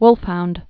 (wlfhound)